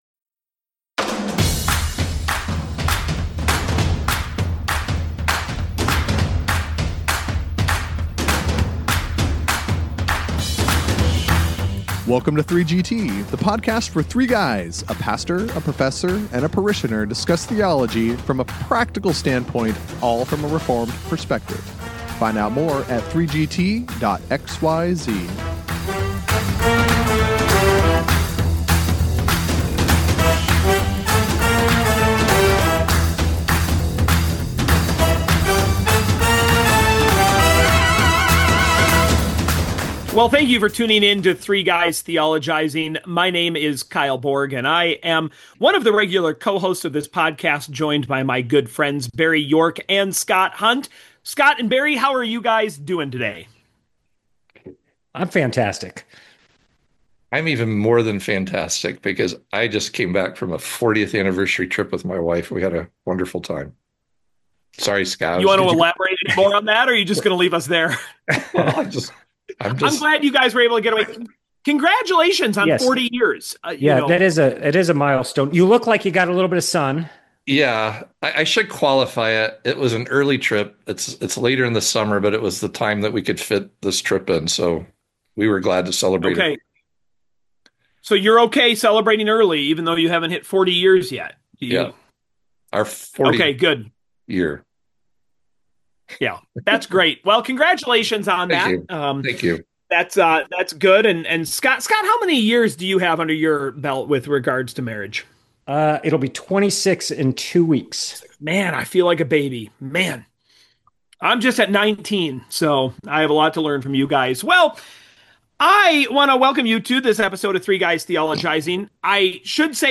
Either way, the three guys get together to have a little summer fun at the expense of artificial intelligence.